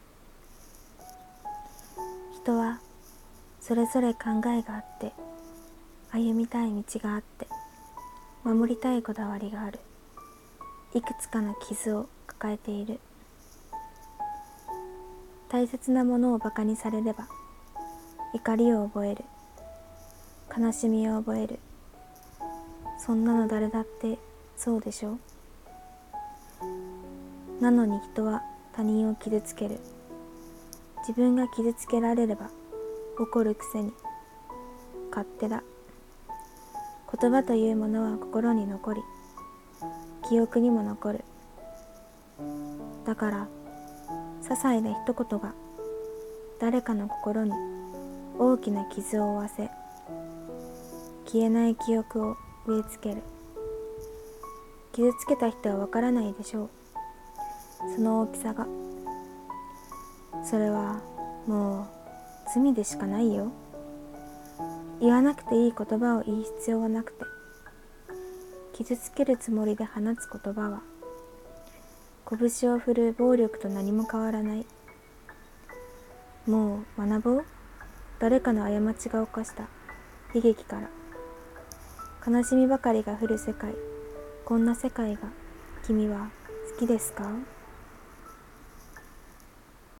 声劇【もういいよ】